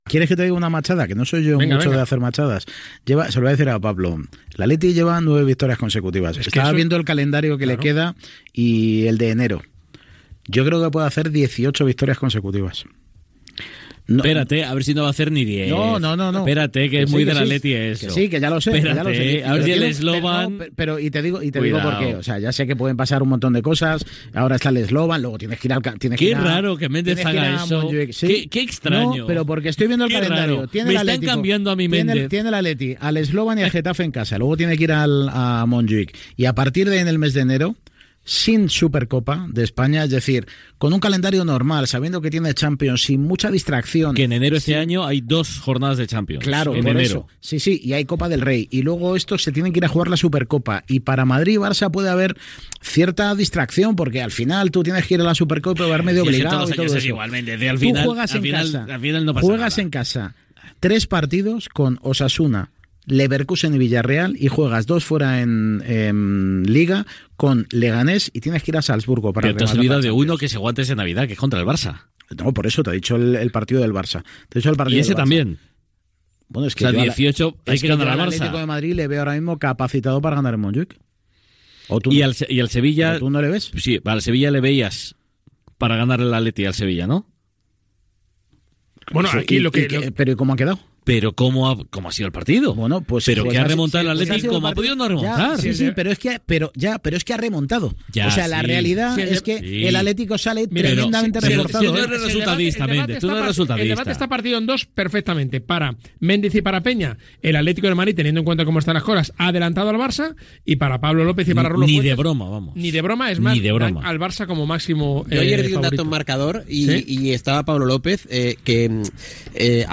Indicatiu del programa, publicitat i comentaris sobre l'actualitat futbolística masculina
Esportiu